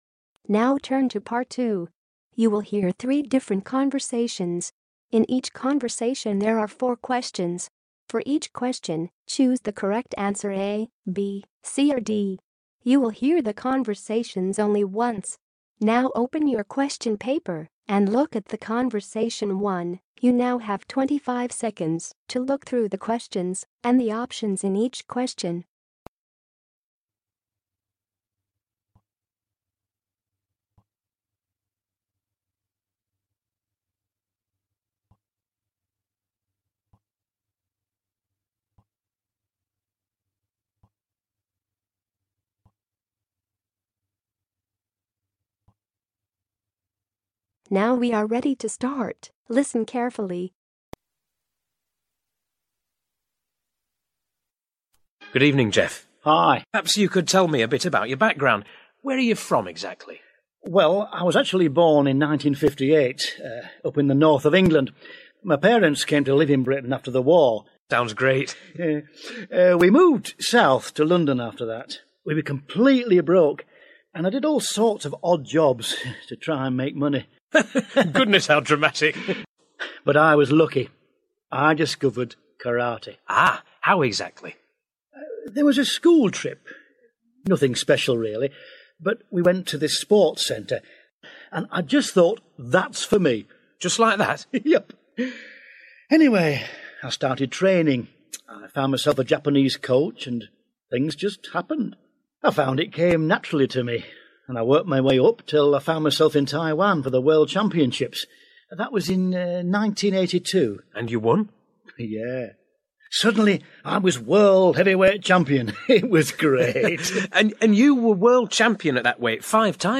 Directions: In this part, you will hear THREE conversations.
Conversation 2. You will hear a man telephoning to talk about the job in a hotel.